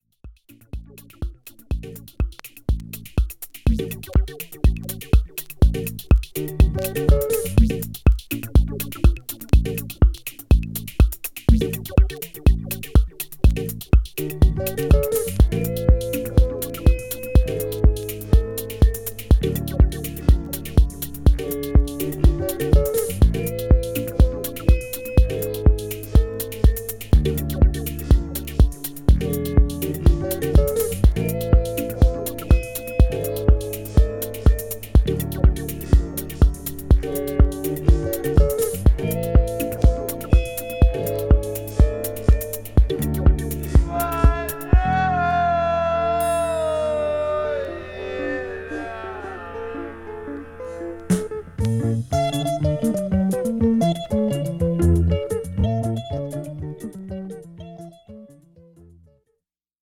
SOUL / FUNK / RARE GROOVE / DISCO